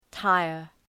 Προφορά
{‘taıər}